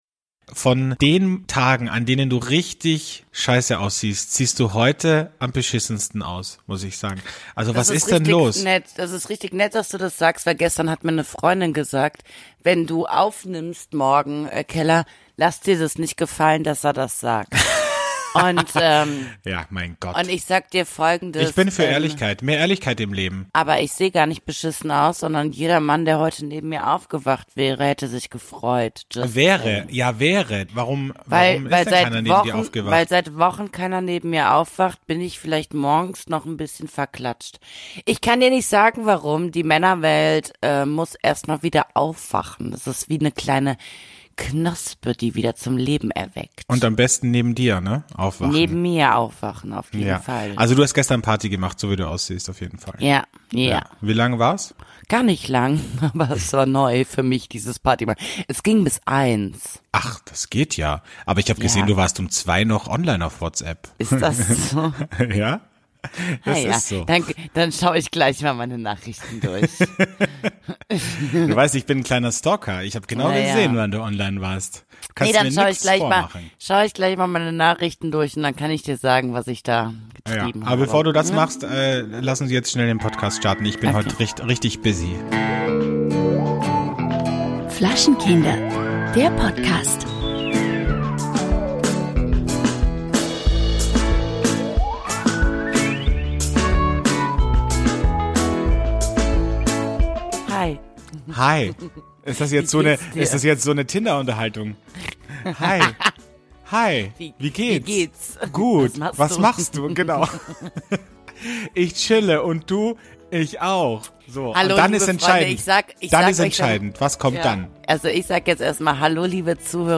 Ihr erlebt sie in Höchstform und noch leicht alkoholisiert.